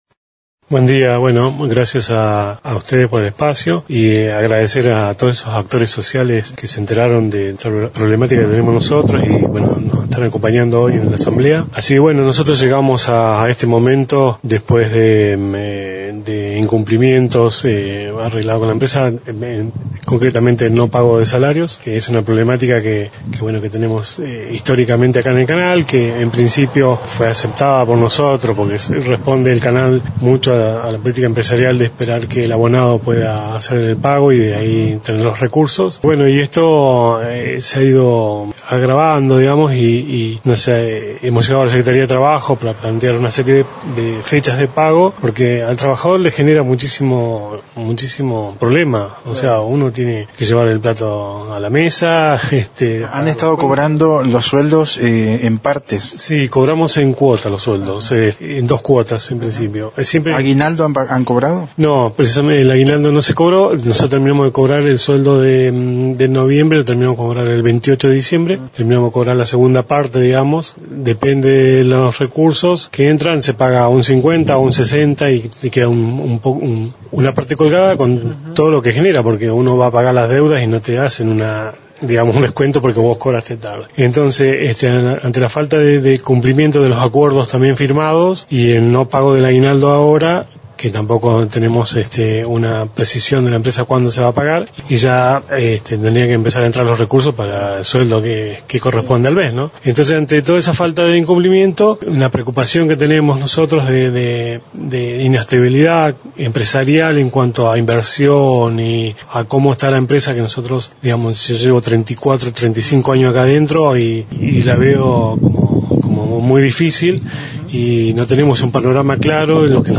a nivel provincial y dialogó con el móvil de Radio Nacional Esquel